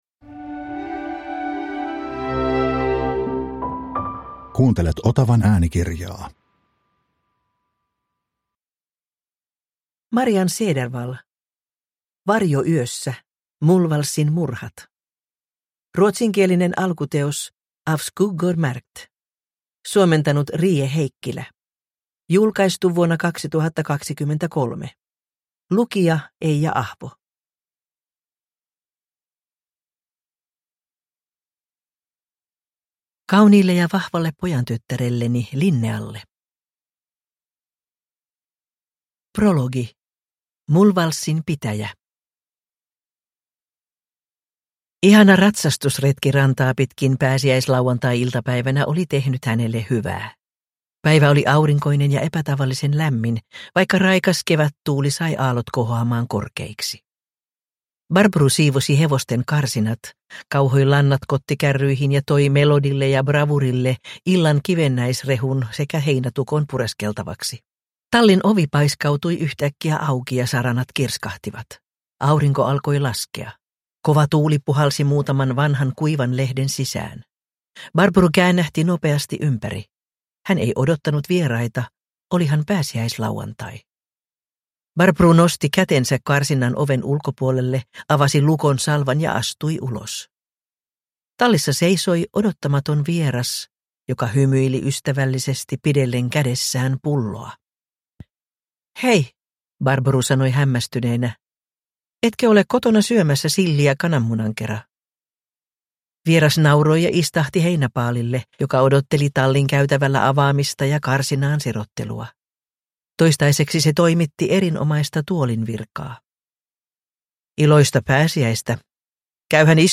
Varjo yössä – Ljudbok – Laddas ner